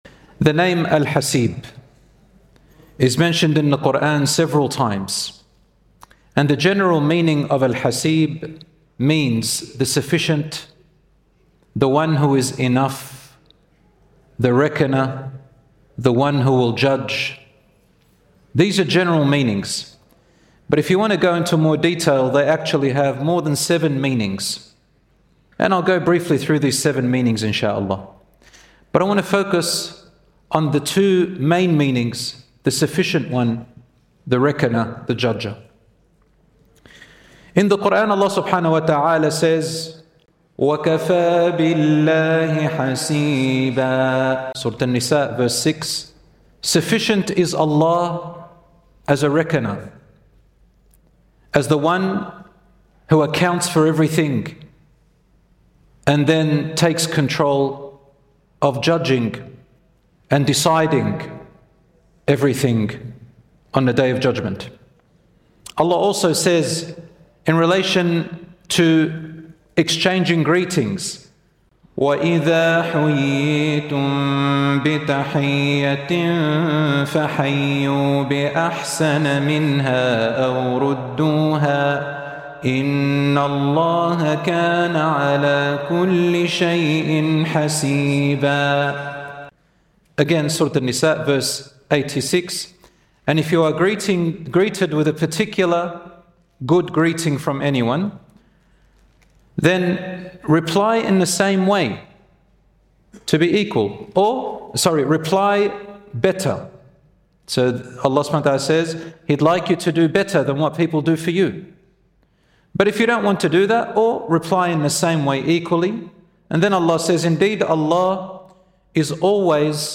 In this lecture, we explore the beautiful name of Allah Al-Haseeb, its deep meanings as the One who is sufficient and perfectly accounts for all things, and how remembering it brings comfort through life’s hardest moments.